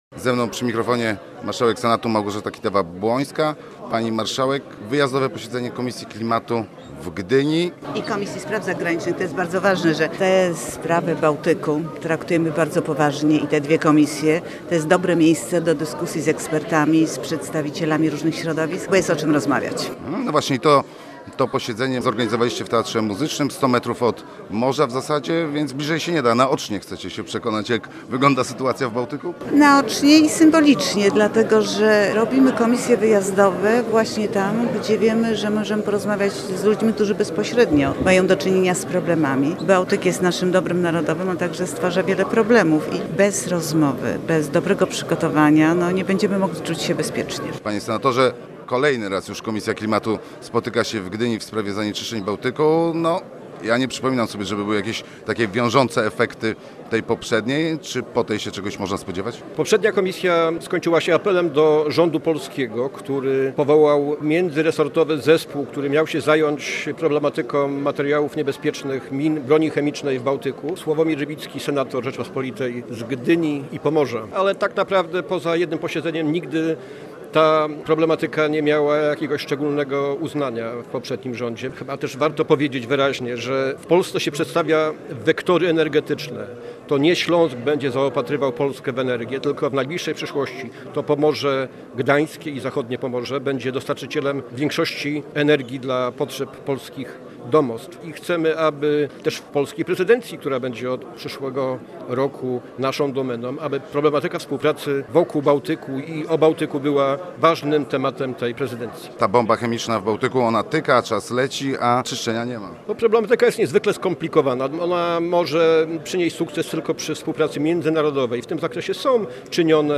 W poniedziałek w Gdyni zebrały się senackie komisje na posiedzeniu poświęconym bezpieczeństwu i ochronie wód Morza Bałtyckiego. Posiedzenie odbyło się w Teatrze Muzycznym imienia Danuty Baduszkowej w Gdyni.